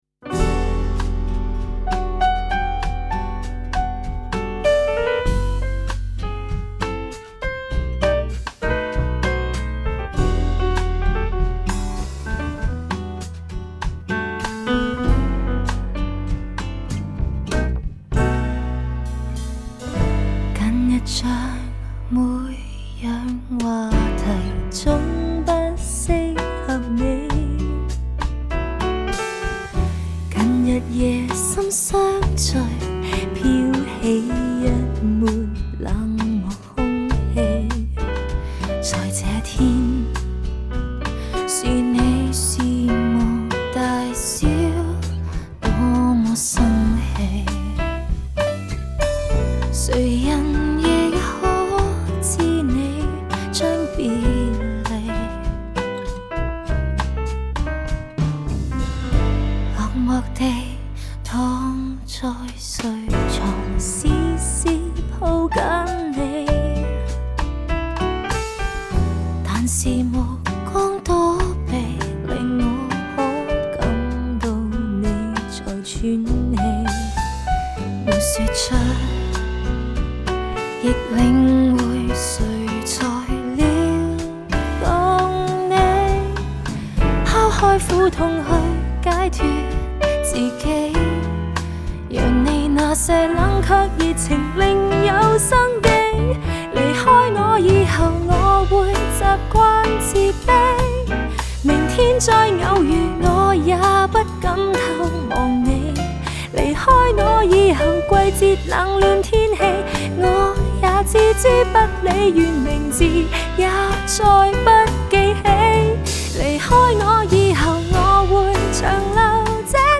Жанр: Cantopop